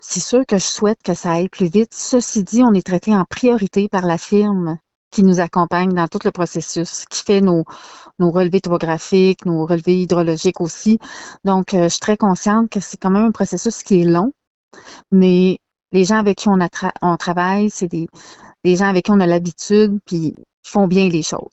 La mairesse de Saint-Célestin Paroisse, Sandra St-Amour, a assuré que le dossier de réparation du glissement de terrain continue de progresser.